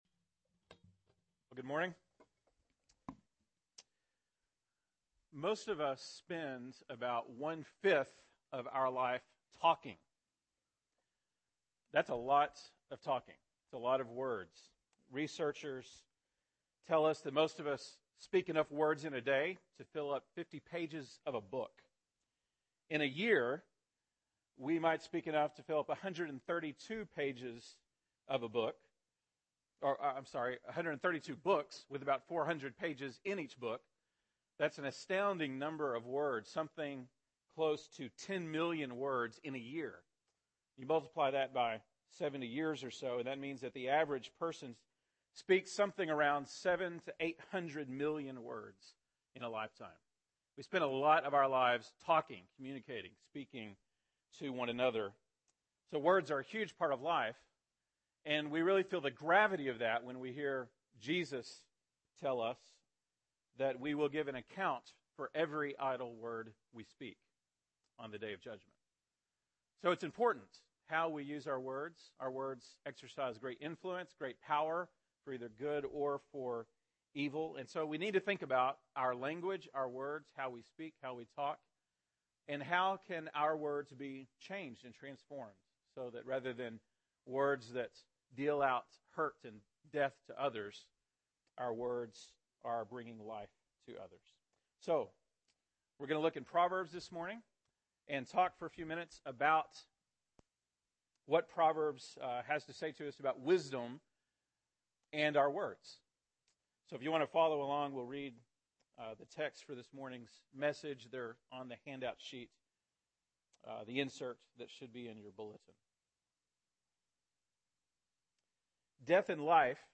August 5, 2012 (Sunday Morning)